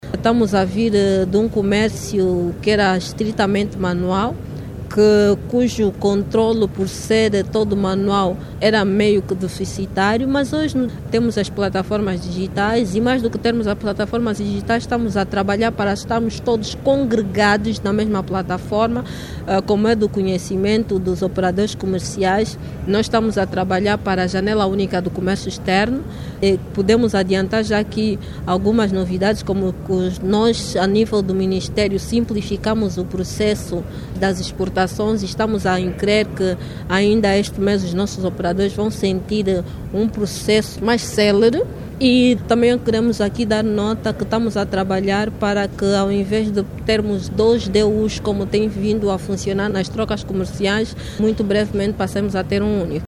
Falando em exclusivo à RNA, a Directora Nacional para o Comércio Externo, Edna Capalo, esclareceu que as reformas em curso no processo de exportações começarão a ser sentidas a partir deste mês.